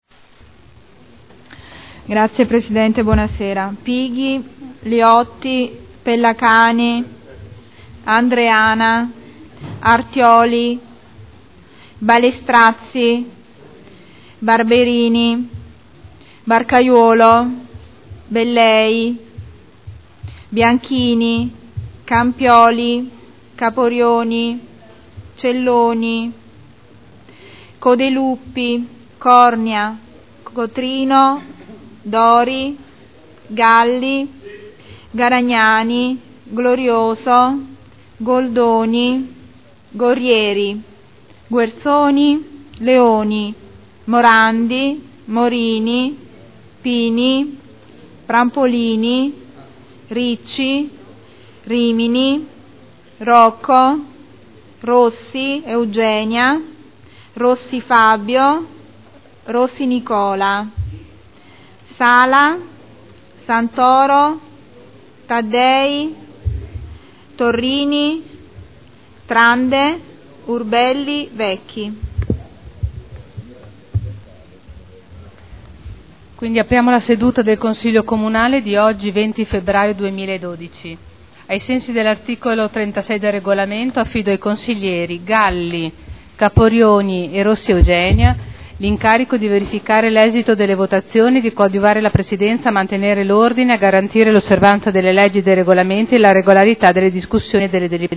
Seduta del 20/02/2012.
Appello.